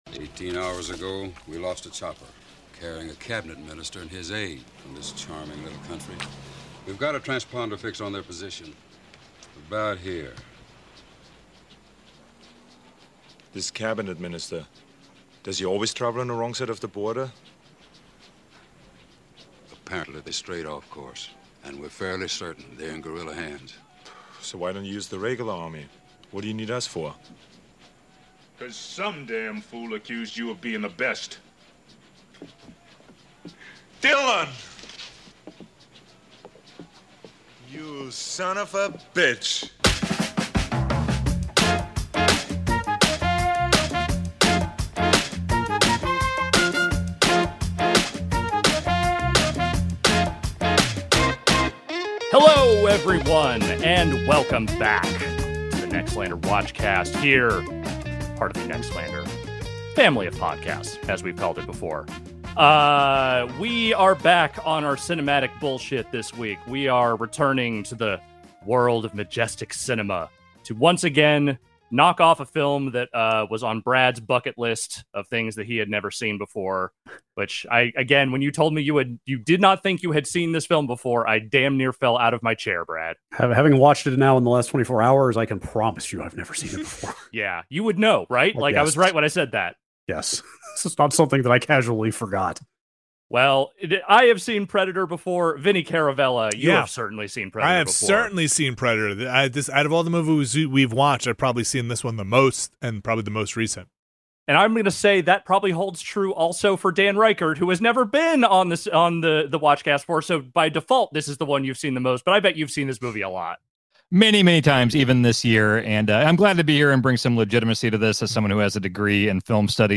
NOTE: One of our individual audio tracks got messed up in this recording, so this was edited using the master mix with everyone's tracks. It all sounds just fine, but wanted to give the heads-up in case anyone noticed a difference.